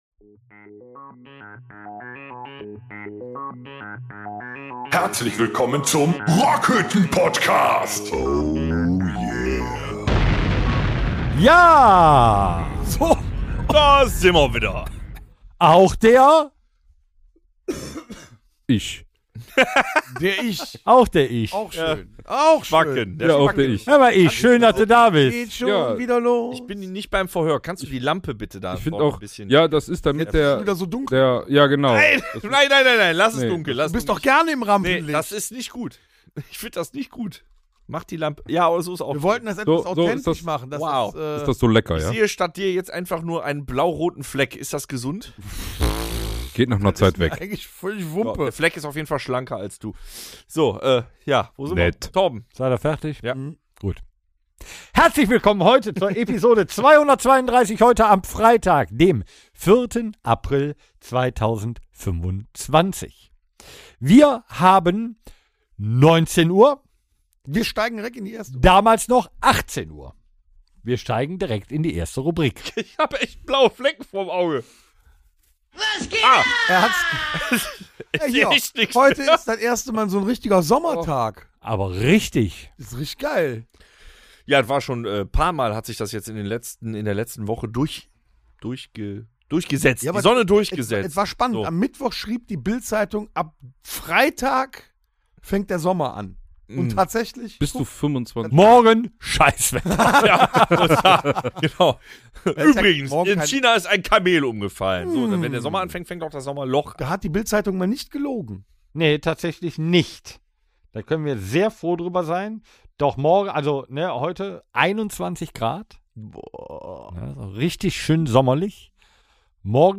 Die ganze Band hat sich vor Beginn des Tourfrühlings versammelt um schonmal auf das exzessive Mobbing Level eines Backstage-Bereiches zu kommen. Neben dem Tourausblick gibt es reichlich Diskussionen zur Zeitumstellung.